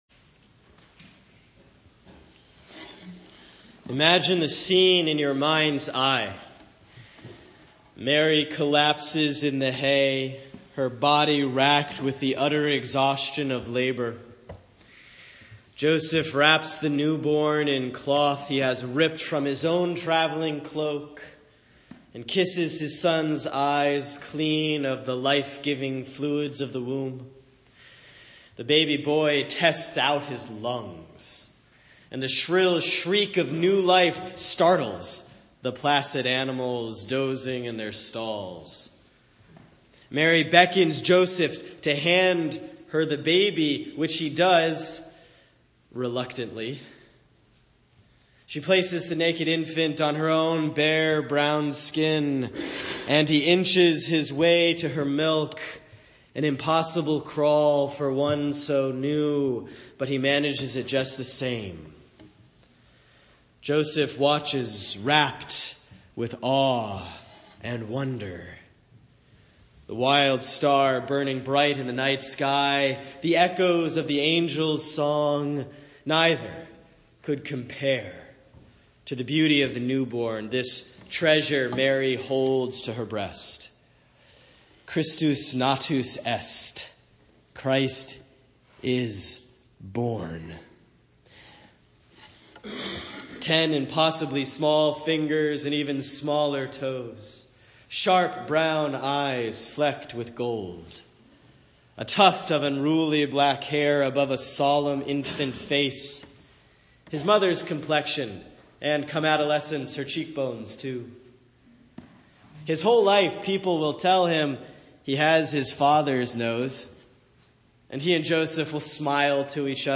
Sermon for Christmas Eve, December 24, 2017 || The Eve of the Feast of the Nativity || Hebrews 1:1-4; John 1:1-14